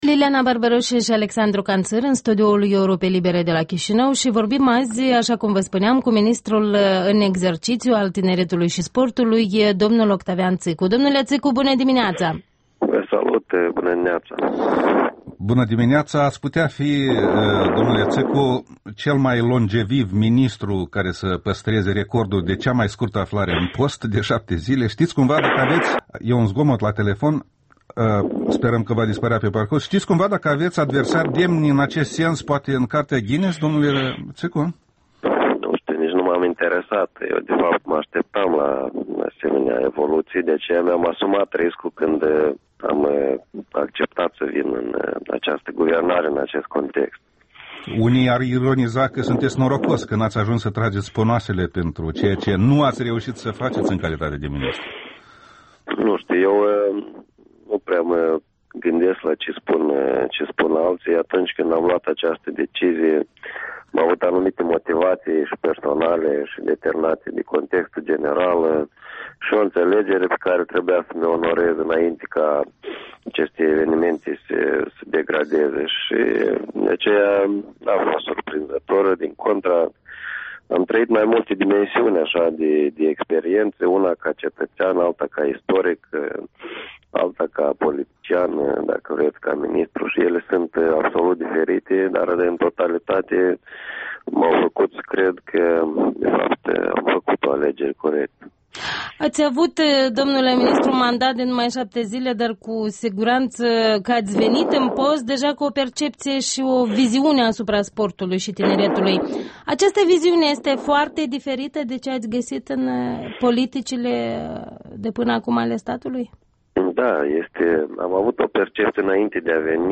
Interviul dimineții cu Octavian Țîcu, ministrul sportului și al tineretului